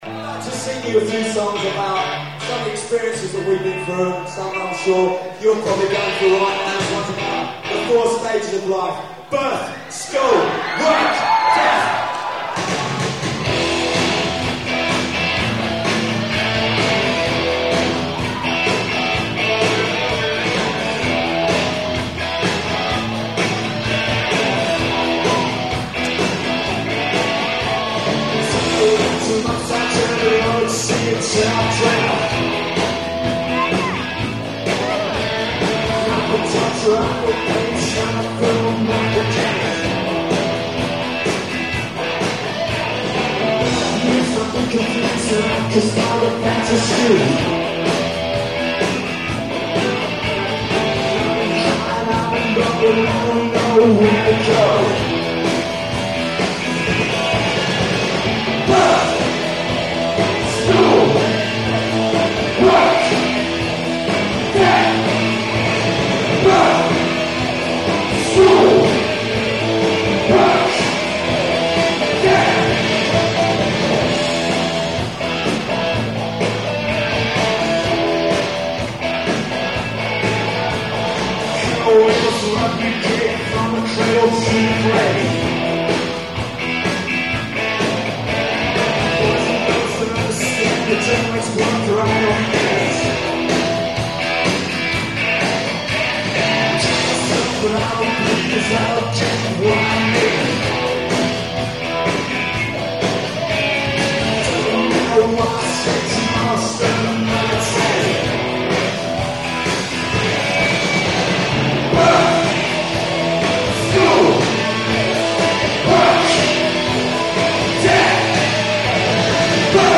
This song was recorded live at Cardiff University in 1988.